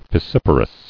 [fis·sip·a·rous]